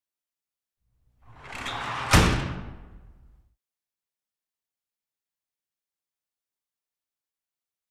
Metal Gate Roll / Slide Close 3, With Slight Reverb.